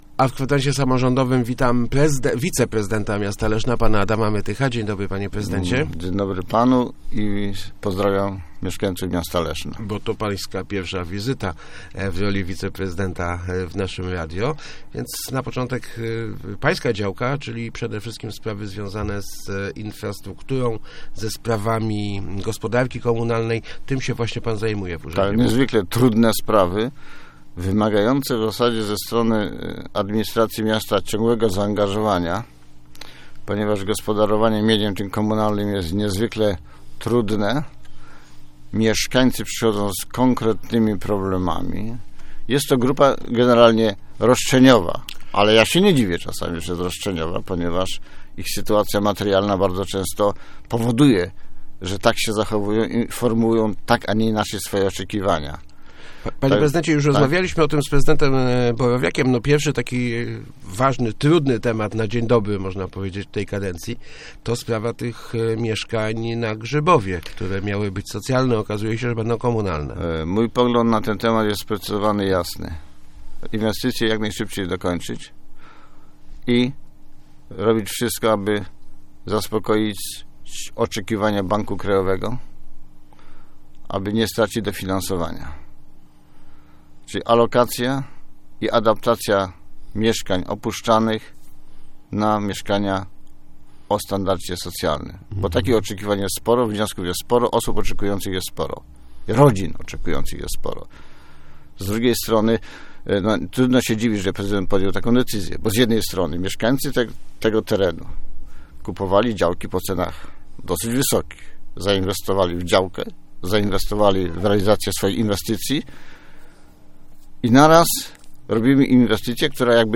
Gościem Kwadransa był wiceprezydent Adam Mytych.